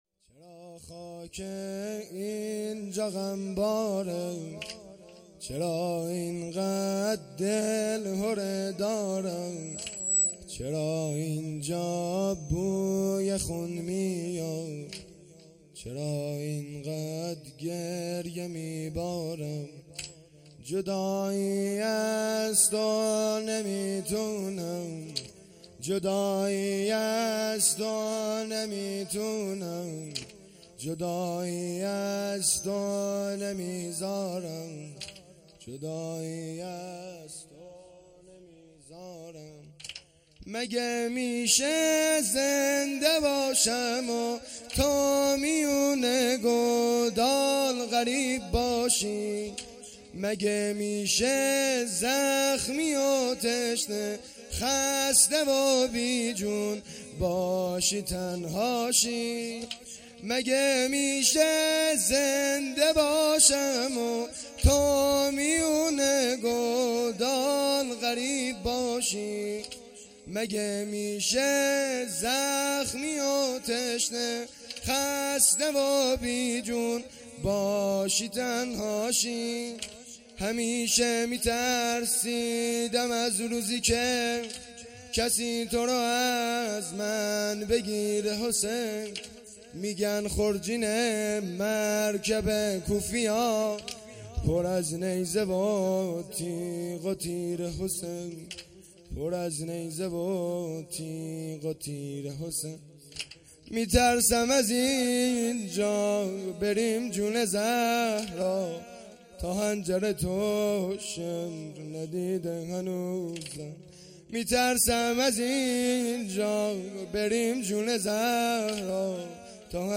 شب دوم محرم الحرام ۱۴۴۳